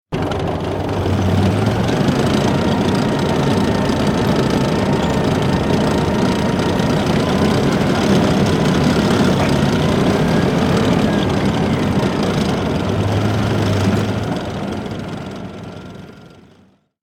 Download Free Tank Sound Effects | Gfx Sounds
War-vehicle-tank-moving-mechanical-noise-4.mp3